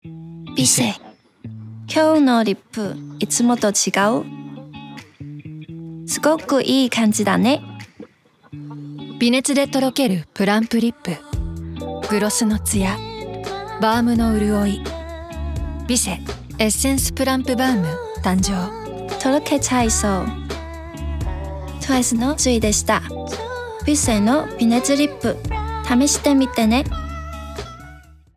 ツウィの#微熱VOICE
voice-tzuyu.wav